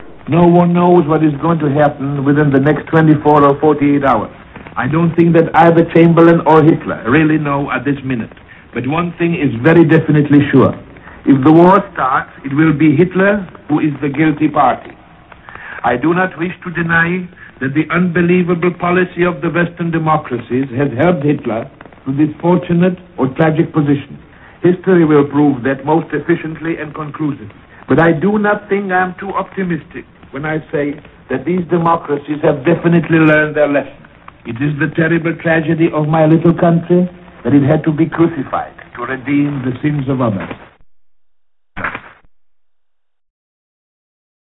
On the eve of the Nazi invasion of Poland, Masaryk spoke out on the situation and the fate of his country on the BBC in London: